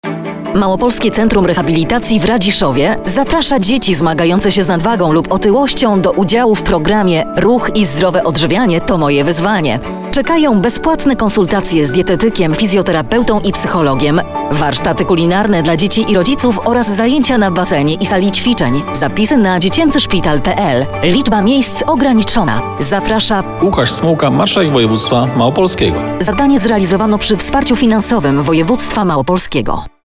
Spot radiowy dot. konsultacji dodatkowych